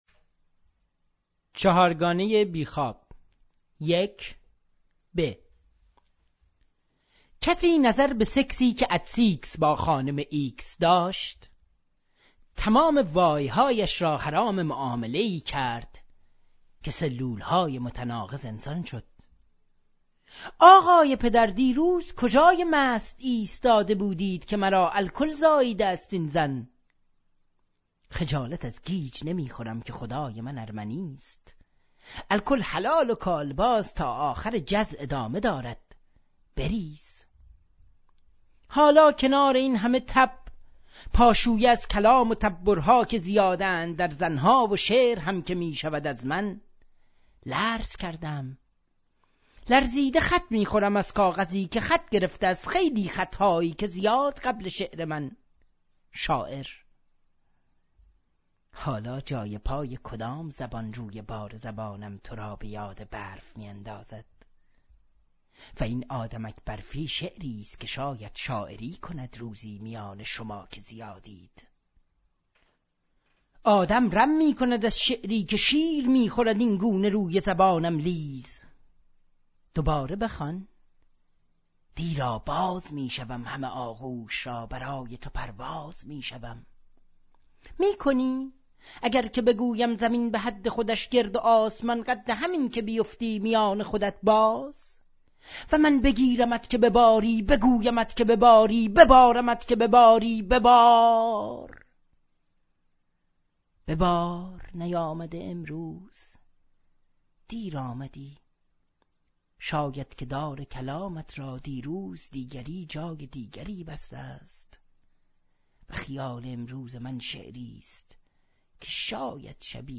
صدای شاعر